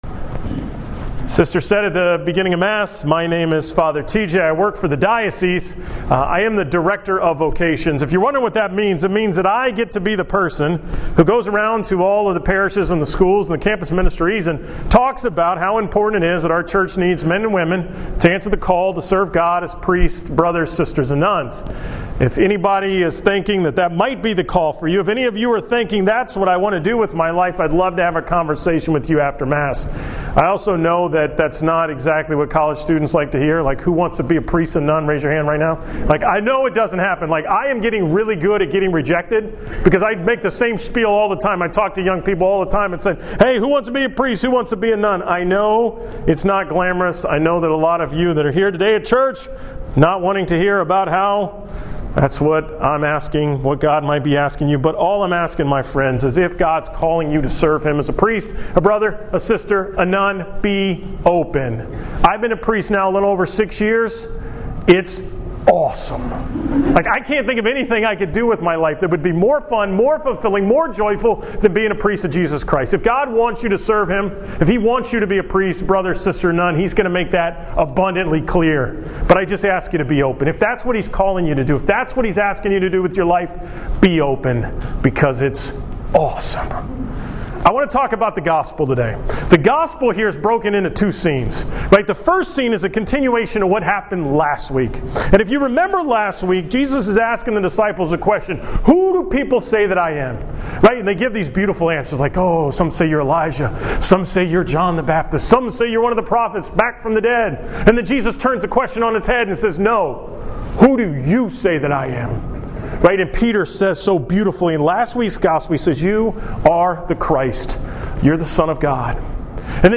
From the 5 pm Student Mass at Rice University on September 20, 2015